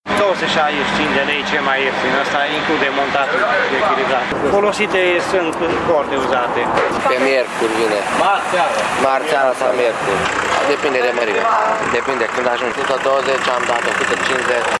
Mai multe service-uri din Târgu Mureș au în acest moment probleme cu stocurile de anvelope, dar așteaptă deja sosirea noilor comenzi: